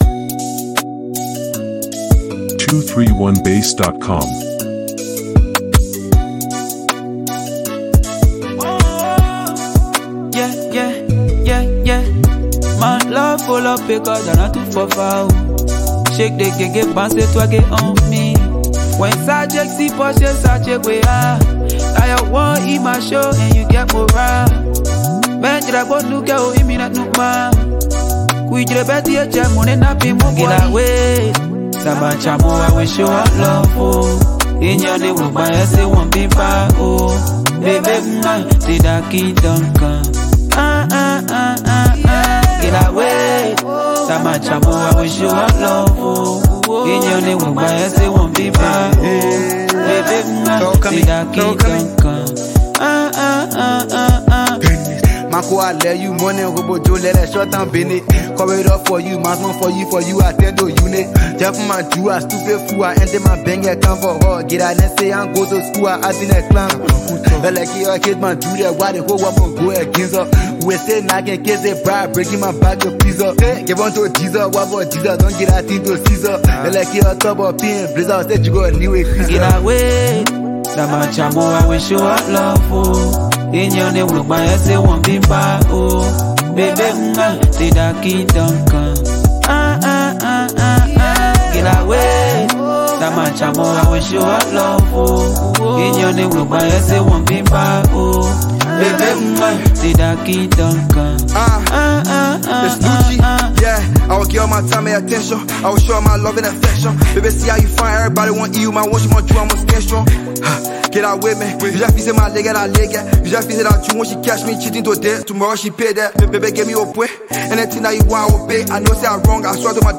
” is a smooth blend of vibes and talent.
this track has layers of Liberian flavors.